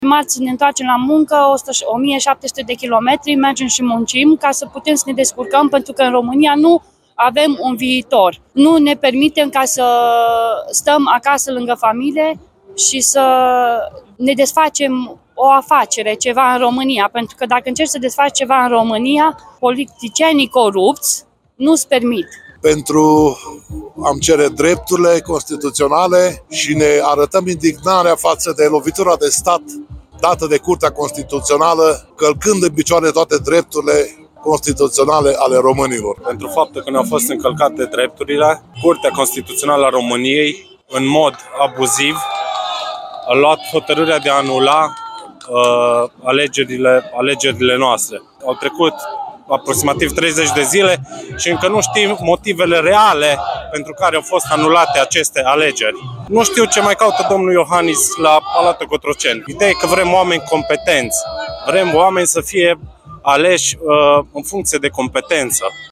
vox-proteste-Arad.mp3